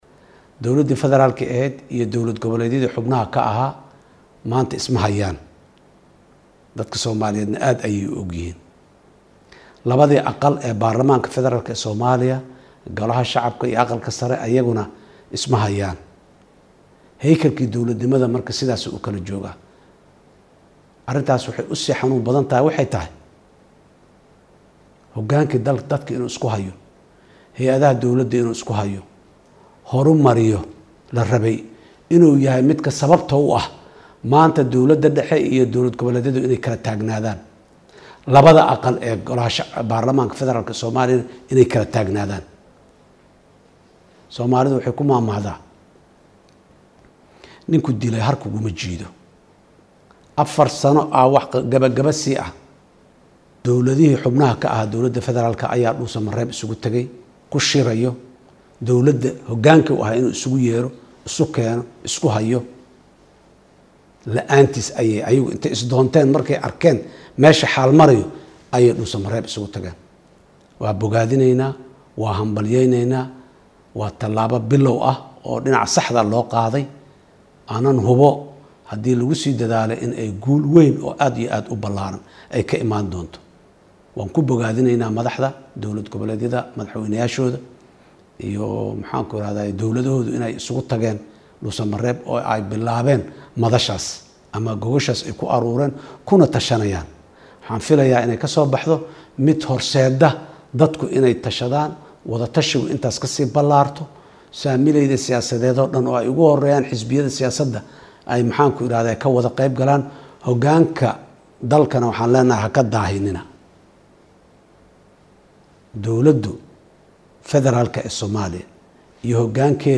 Madaxweynihii hore ee Soomaaliya Xasan Sheekh Maxamuud oo la hadlay warbaahinta ayaa sheegay in dowlada Federaalka Soomaaliya ay qaadi doonto cawaaqibta ka Timaada haddii la carqaladeeyo shirka madaxda dowlad goboleedyada dalka.